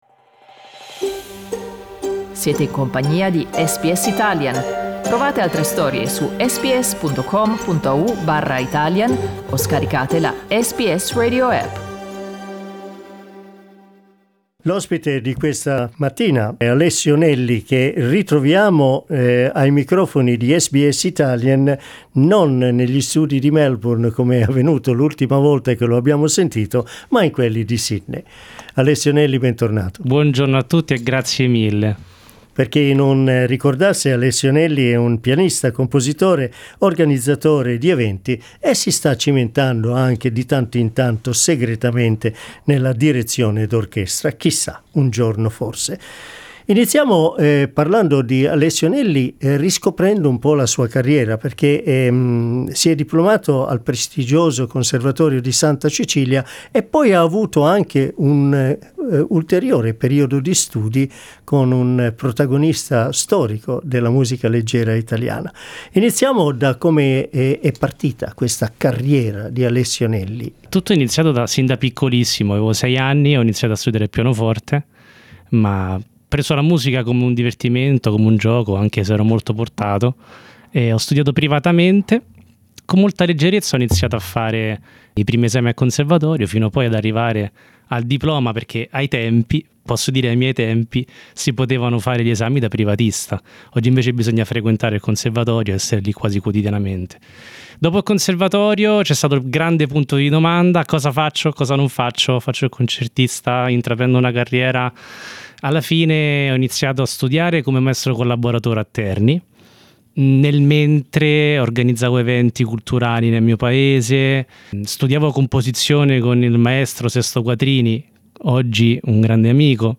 In conversation with composer and musician